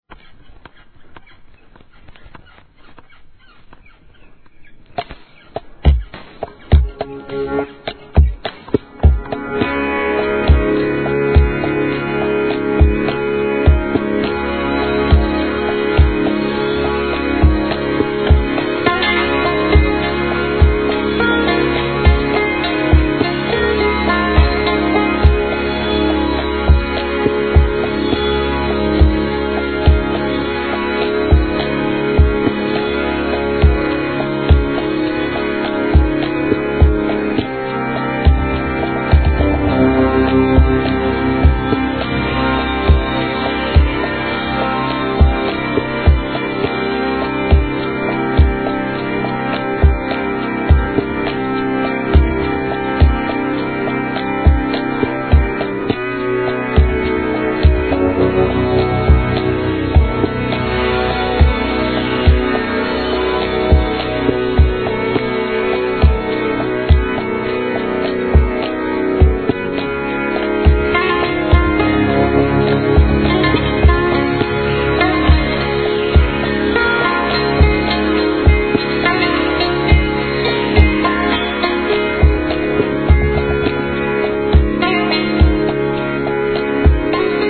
VOCALレスでのエレクトロ且つアブストラクトなINST.物!!